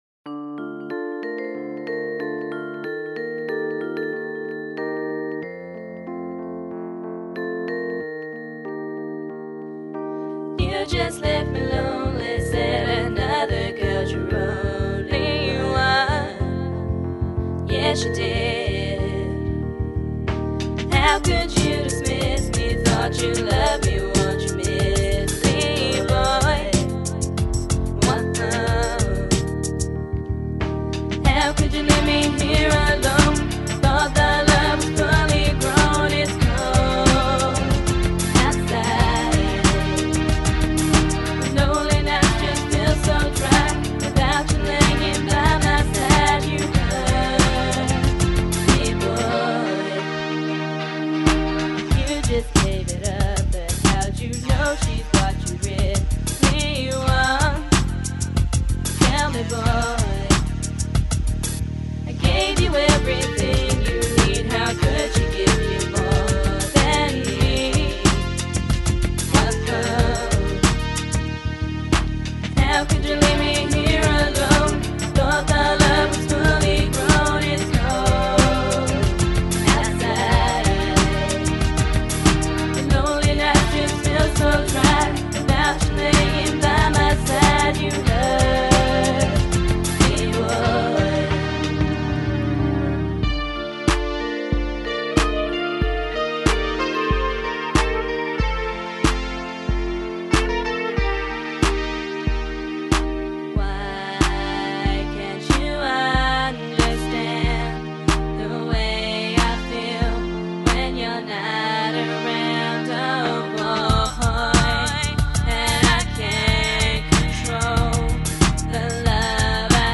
dance/electronic
pop track
RnB